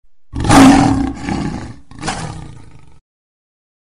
Lion Roar
Lion Roar is a free animals sound effect available for download in MP3 format.
482_lion_roar.mp3